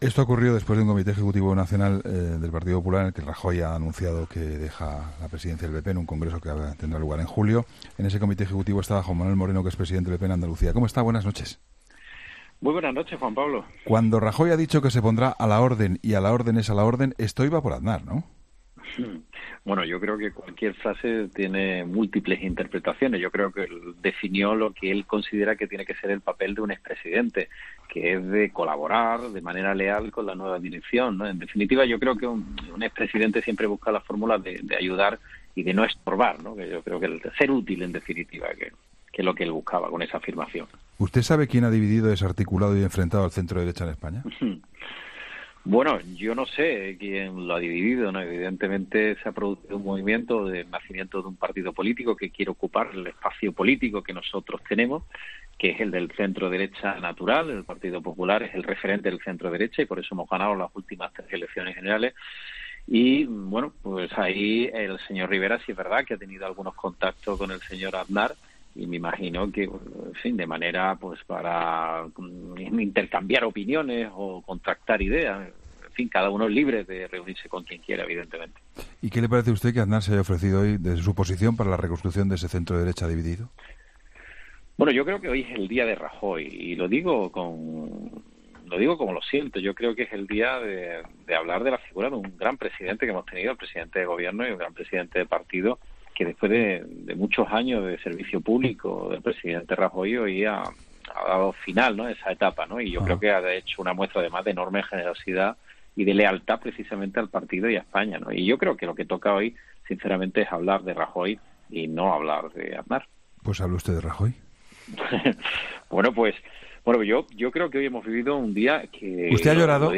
Juan Manuel Moreno, presidente del PP en Andalucía, ha destacado en 'La Linterna' con Juan Pablo Colmenarejo la gestión de Mariano Rajoy al frente...